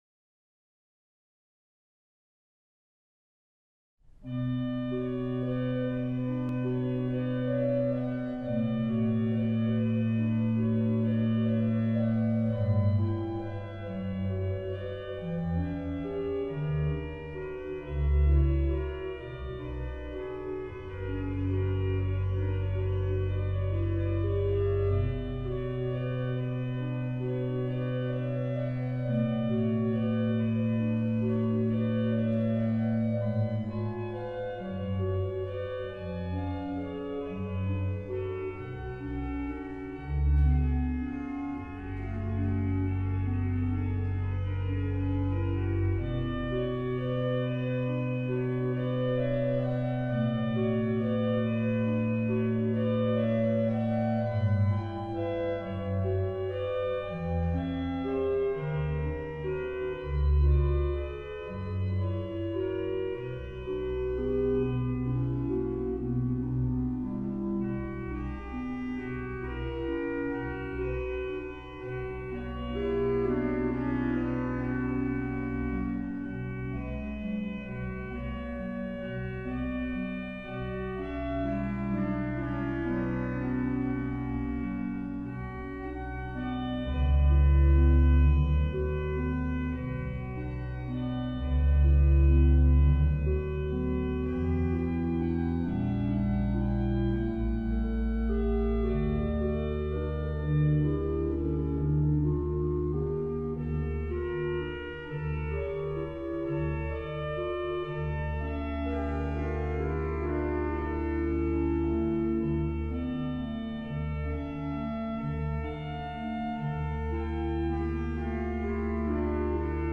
Naast allerlei meer of minder bijzondere voorwerpen die met kerkmuziek te maken hebben zijn in deze tentoonstelling orgelwerken te horen uit vijf Delftse kerken gespeeld door Delftse organisten.
orgel